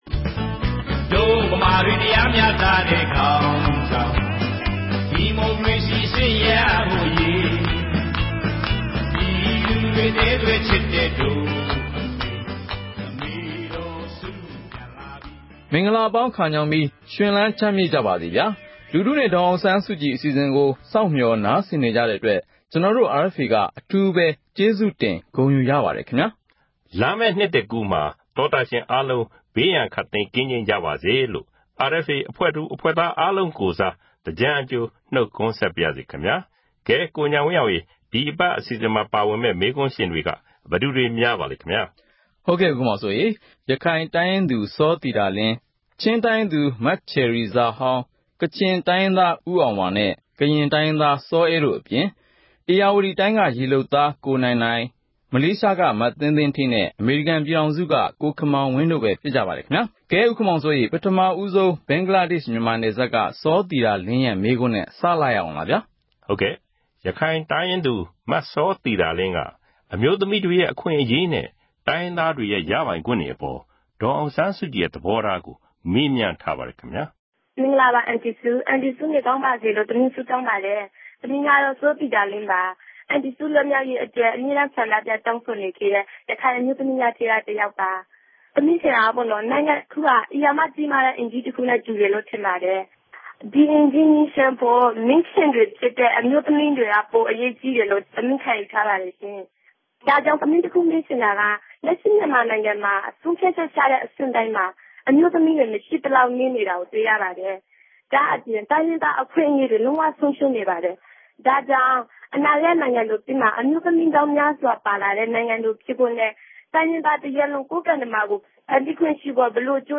လူထုနဲ့ ဒေါ်အောင်ဆန်းစုကြည် အစီအစဉ်ကို RFA က အပတ်စဉ် သောကြာနေ့ ညတိုင်းနဲ့ ဗုဒ္ဓဟူးနေ့ မနက်တိုင်း တင်ဆက်နေပါတြယ်။ ဒီ အစီအစဉ်ကနေ ပြည်သူတွေ သိချင်တဲ့ မေးခွန်းတွေကို ဒေါ်အောင်ဆန်းစုကြည် ကိုယ်တိုင် ဖြေကြားပေးမှာ ဖြစ်ပါတယ်။